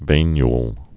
(vānyl)